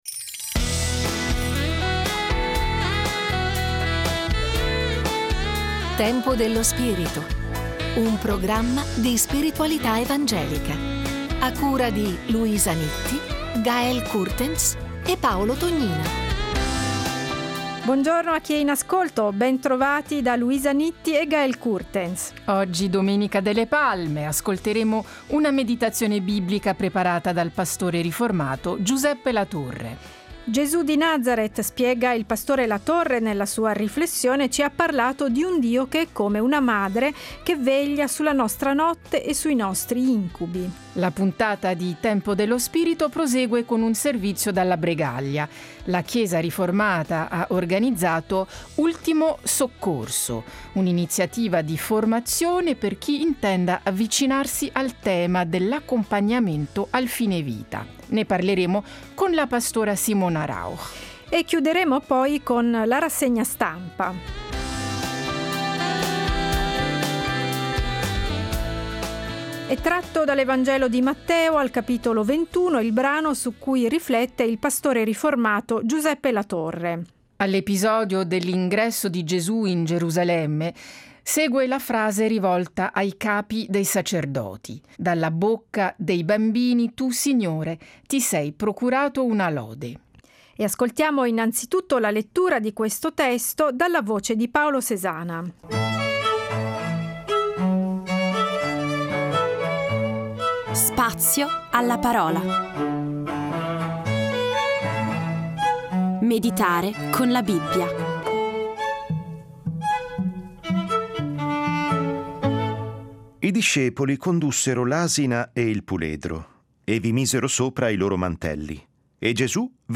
In che modo aiutare le persone che giungono alla fine della loro vita? “Ultimo soccorso” è un’iniziativa promossa dalla Chiesa riformata di Bregalia, per insegnare alle persone interessate l’accompagnamento nelle ultime fasi della vita, mettendo al centro il concetto di cura globale della persona. Intervista